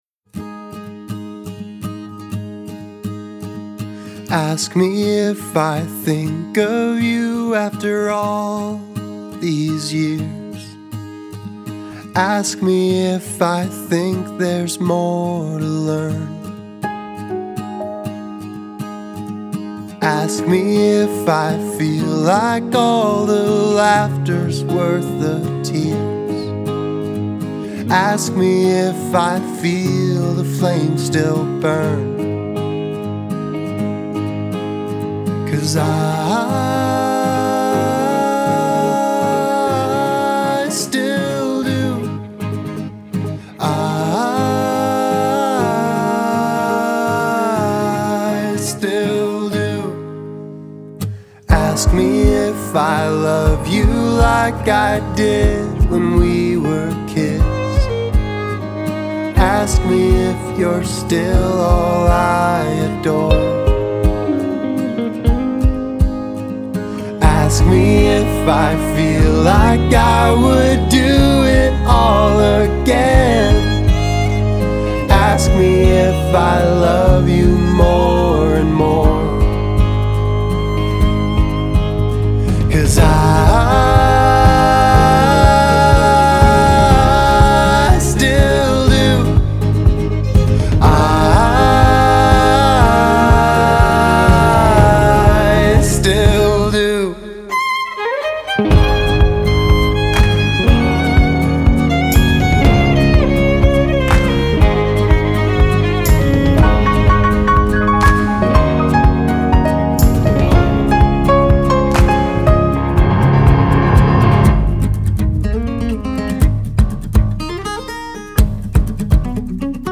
Folk/Americana
(credits: producer, co-writer)